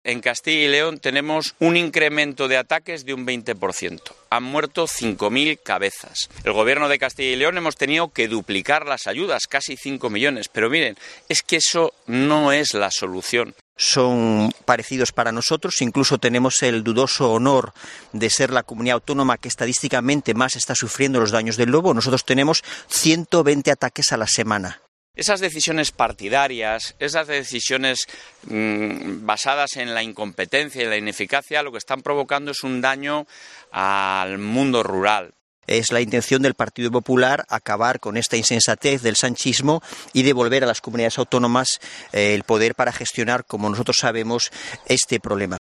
Mañueco y Canga denuncian en Cabrales el aumento de ataques del lobo
Así lo ha aseverado el también presidente de la Junta en un acto celebrado en el municipio asturiano de Cabrales, donde ha acompañado al candidato del PP a la presidencia del Principado de Asturias, Diego Canga, y a otros candidatos municipales del Partido Popular.